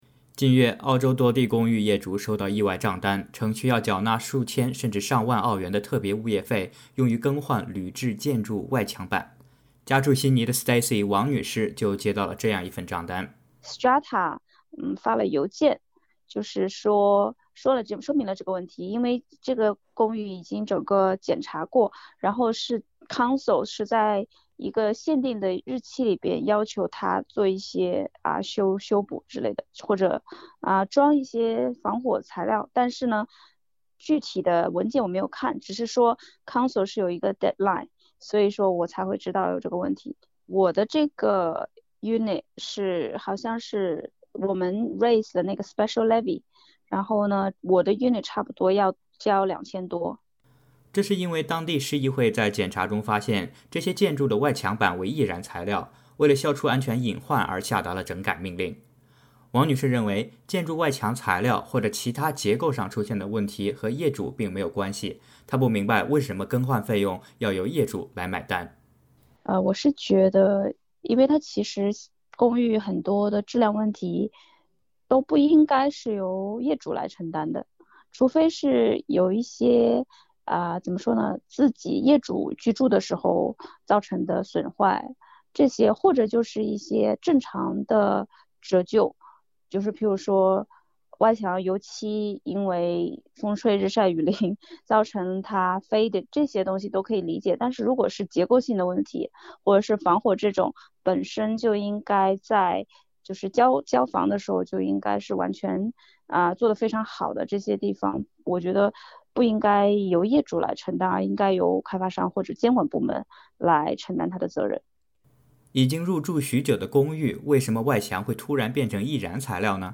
完整采访请点击页面上方音频收听。